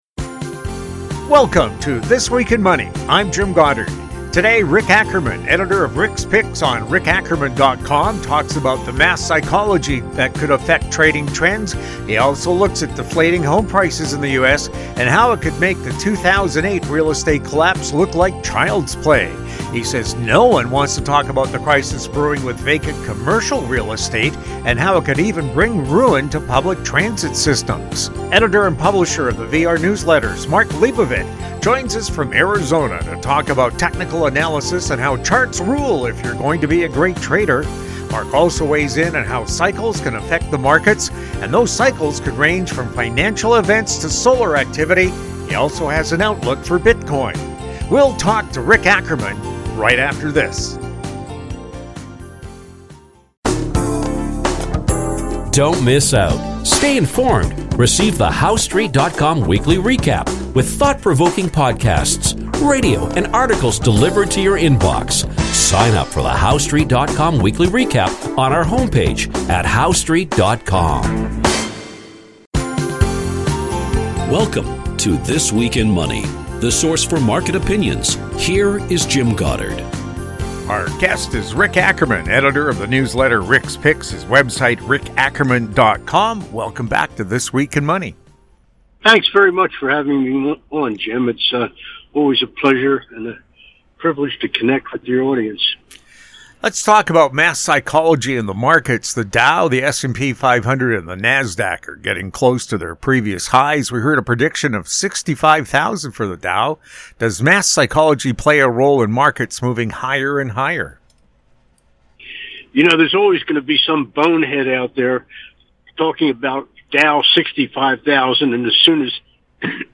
New shows air Saturdays on Internet Radio.